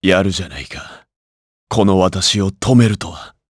Lusikiel-Vox_Dead_jp.wav